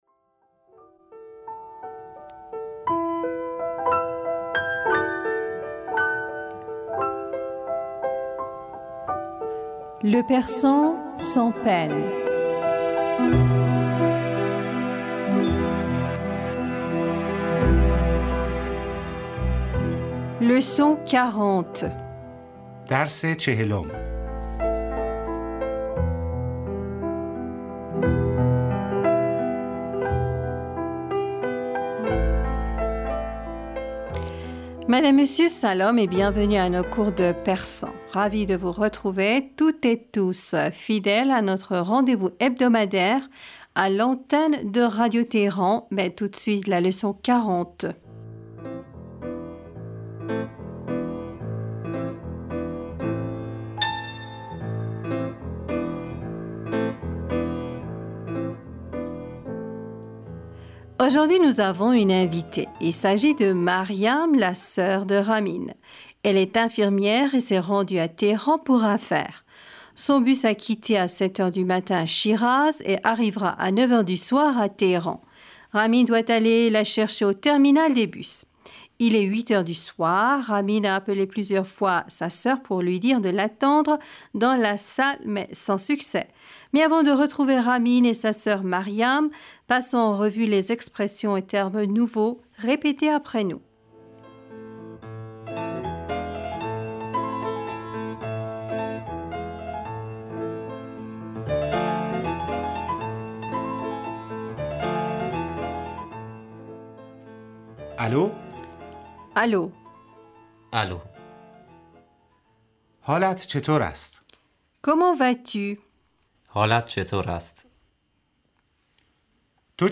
Salam et bienvenus à nos cours de persan.
Ravis de vous retrouver, toutes et tous, fidèles à notre rendez-vous hebdomadaire à l'antenne de Radio Téhéran.
Mais avant de retrouver Ramin et sa sœur Maryam, passons en revue les expressions et termes nouveaux. Répétez après nous.